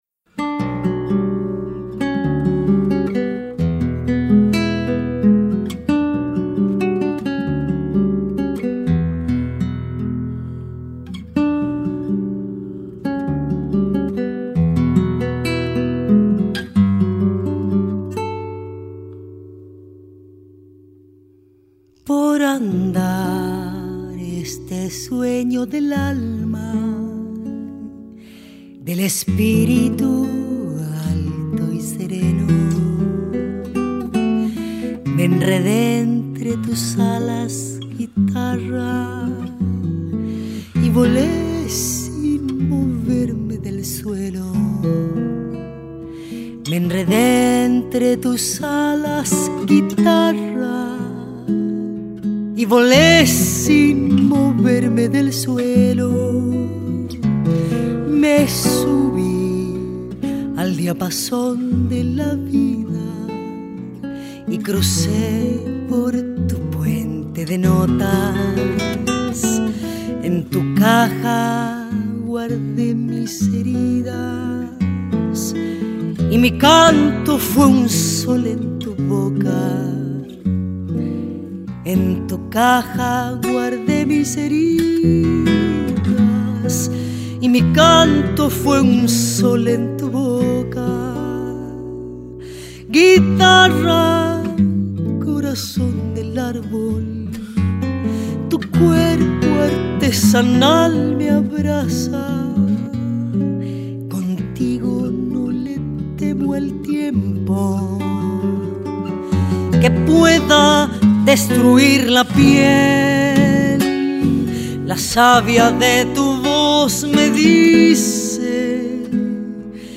Zamba